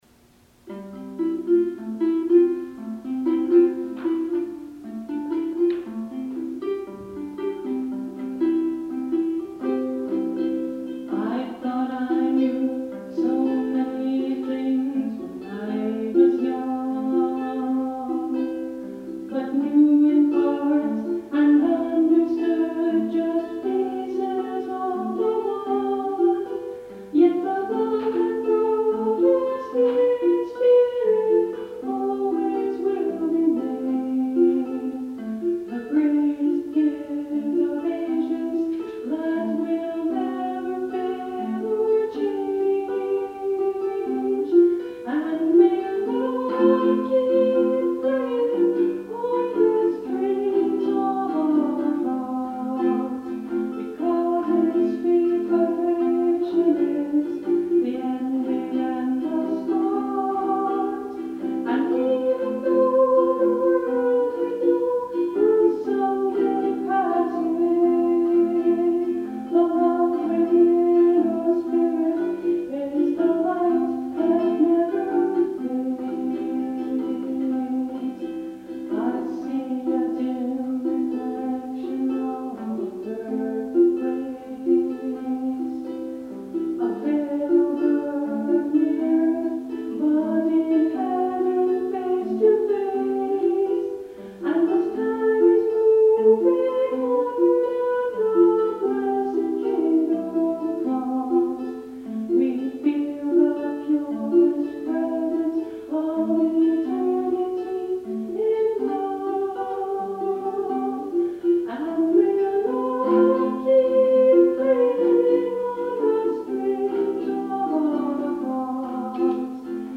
During our wedding ceremony, I played two original songs.
In the audio player above, you’ll here the live recordings from our wedding ceremony.